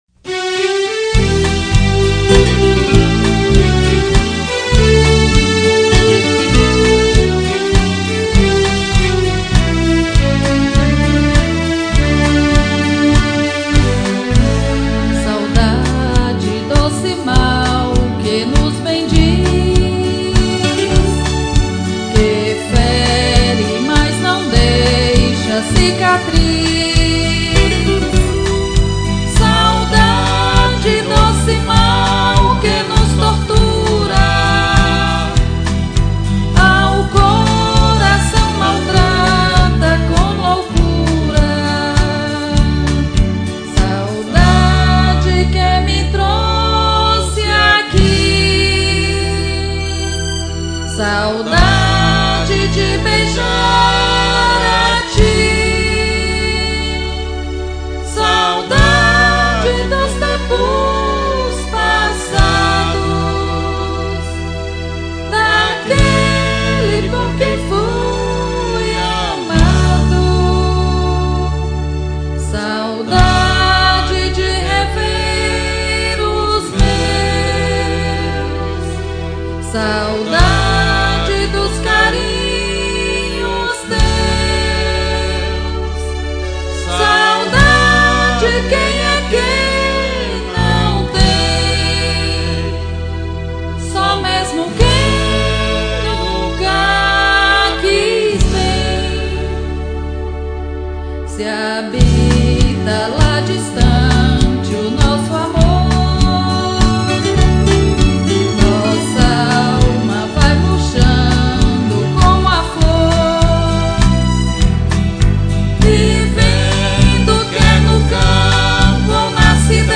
MODINHAS